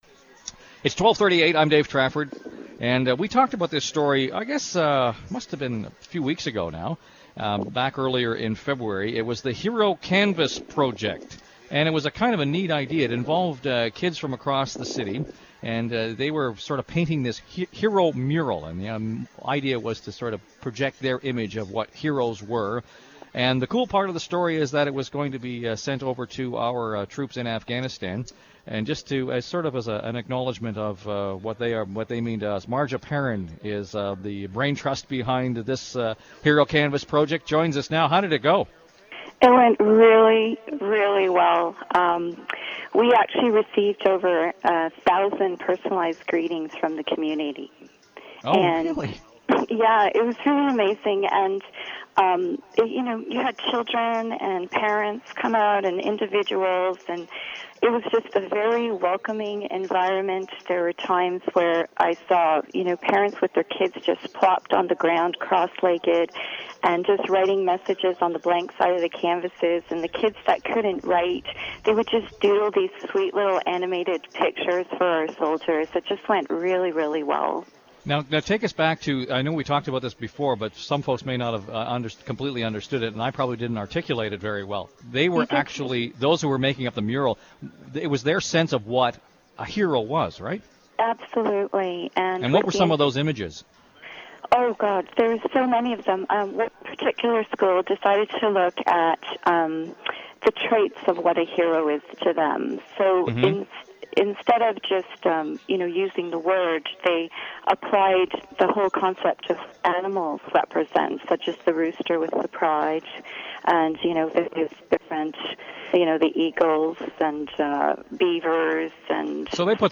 02 Hero Canvas Project Post-event_CFRB Radio Interview.m4a